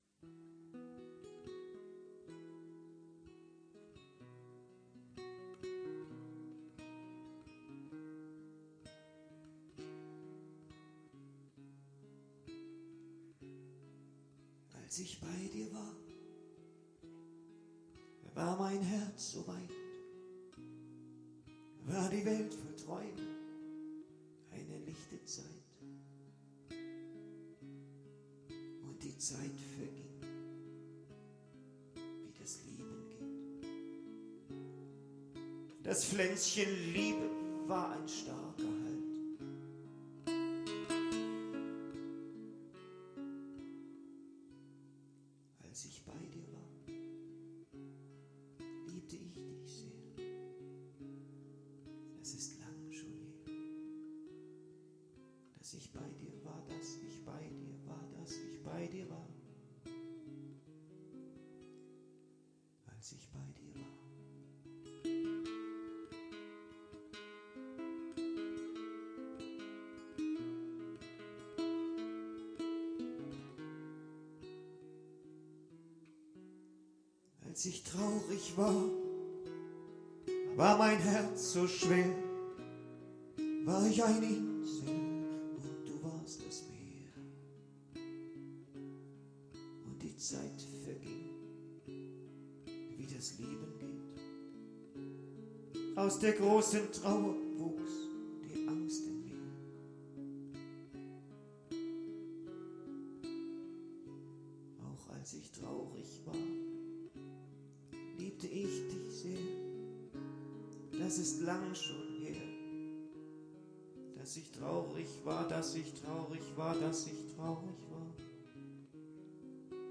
ein vorerst letzter höreindruck vom mitschnitt des konzerts